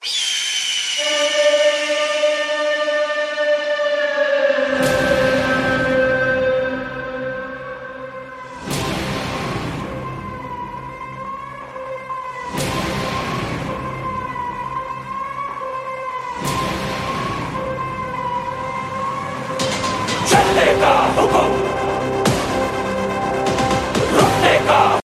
It gives your phone a strong and cinematic vibe.